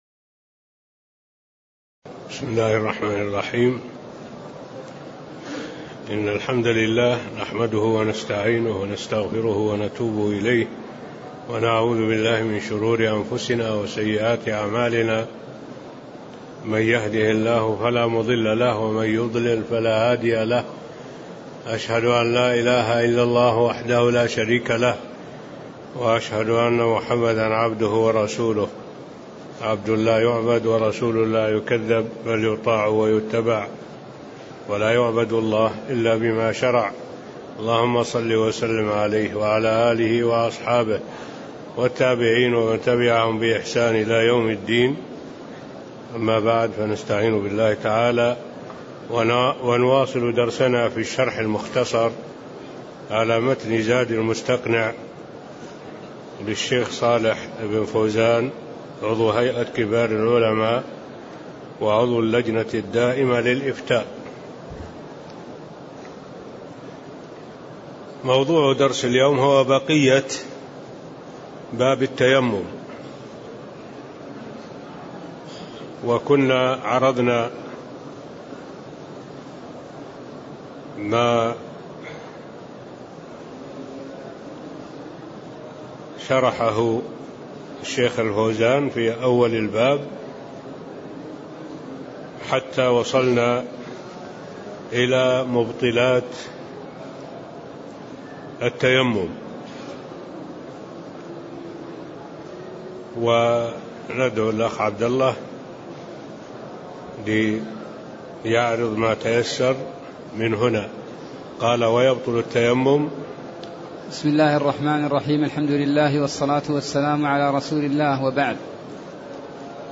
تاريخ النشر ٧ ربيع الثاني ١٤٣٤ هـ المكان: المسجد النبوي الشيخ: معالي الشيخ الدكتور صالح بن عبد الله العبود معالي الشيخ الدكتور صالح بن عبد الله العبود باب التيمم (10)